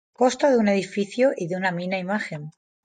Pronounced as (IPA) /ˈmina/